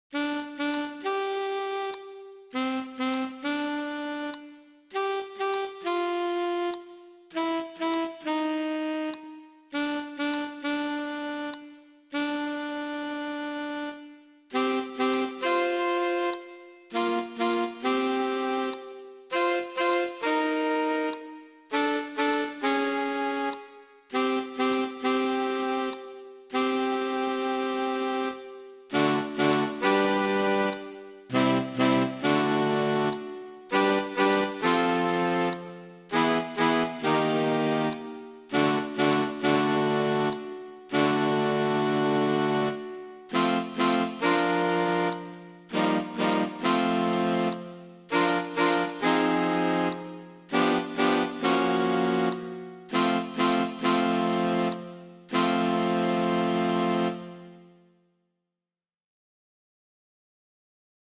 In deze inzingoefening werk je met het koor toe naar driestemmige parallelle majeur akkoorden, waarbij een vierde stem de grondtoon blijft zingen.
In de laatste stap laat je één van de stemmen dezelfde ritmes zingen, maar steeds op de grondtoon.
De vier bovenstaande oefeningen klinken als volgt:
Inzingoefening-met-parallellen-plus-liggende-stem.mp3